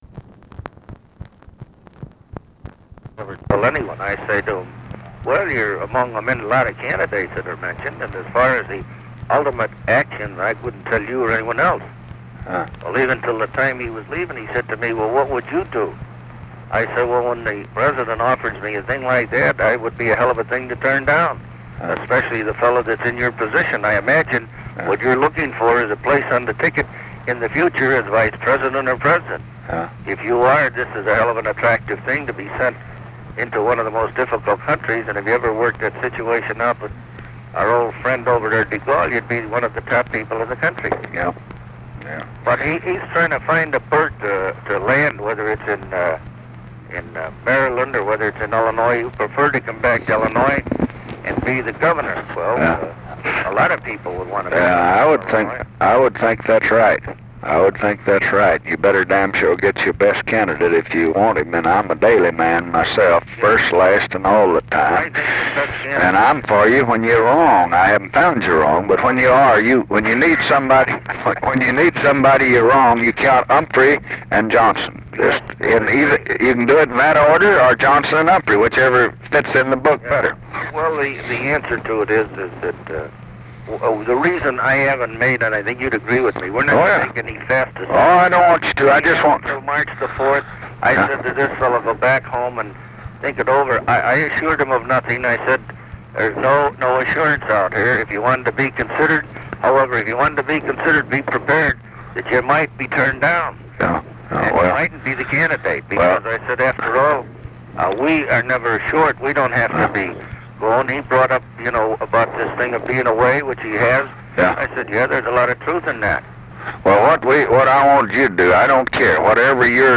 Conversation with RICHARD DALEY and HUBERT HUMPHREY, January 27, 1968
Secret White House Tapes